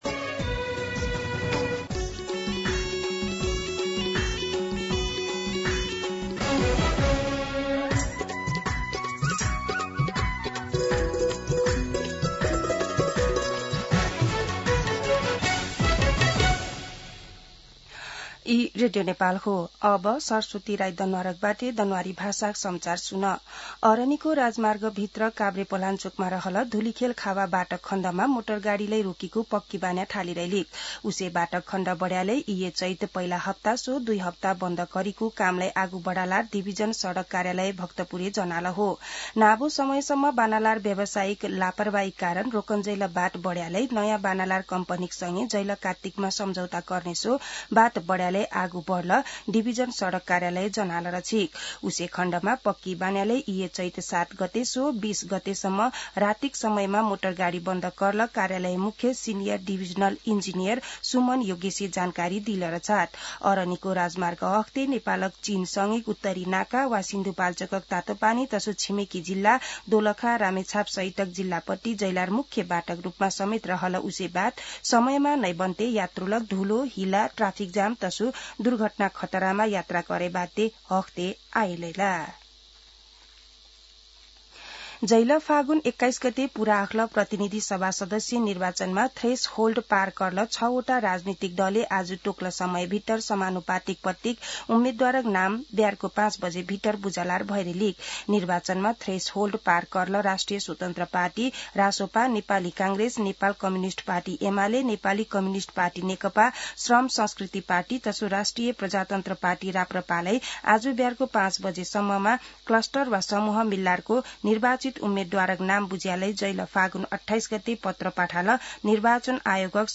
दनुवार भाषामा समाचार : १ चैत , २०८२